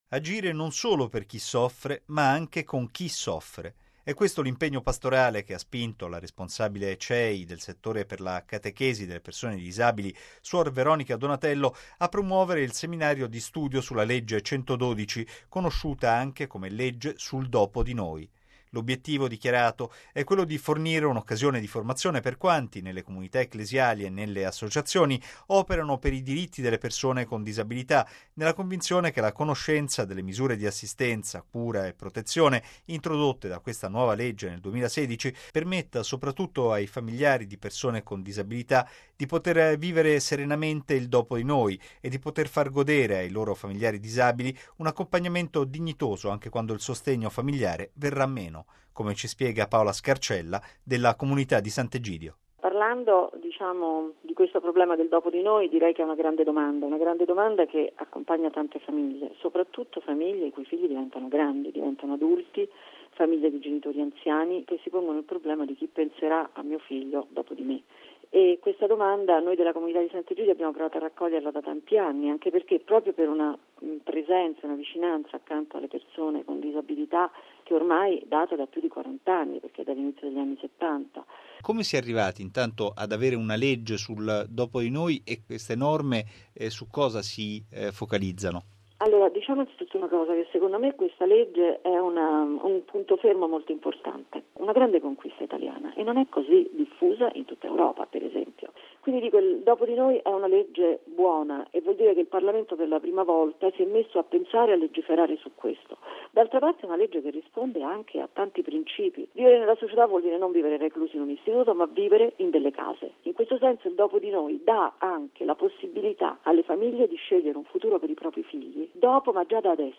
E’ conosciuta come la Legge sul Dopo di Noi, ma il suo vero nome è legge 112 del 2016 e il suo obiettivo è permettere che nascano tutte le misure di assistenza, cura e protezione necessarie alle persone con disabilità senza sostegno familiare. Se ne è parlato in un convegno che si è svolto a Bologna, organizzato dalla Conferenza episcopale italiana.